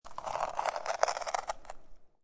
Download Free Dig Dug Sound Effects